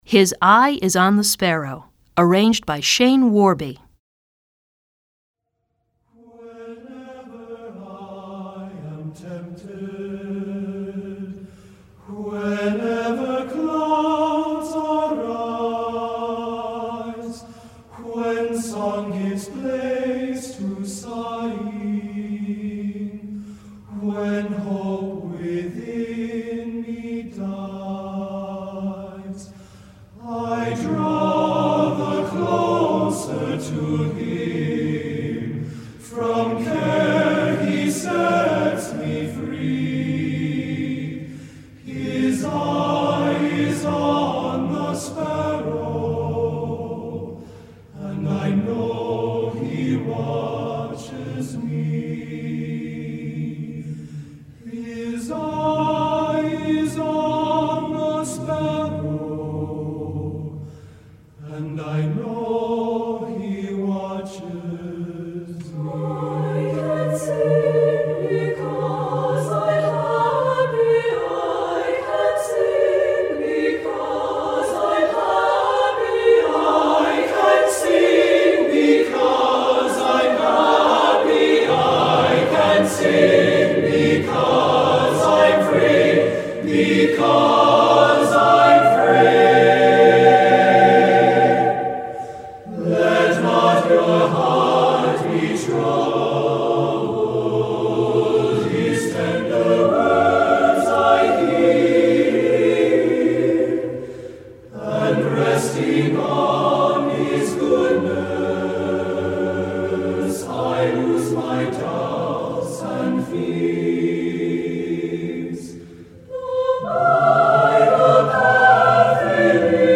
Choeur Mixte (SATB)